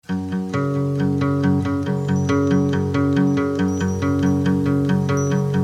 moody alternative/pop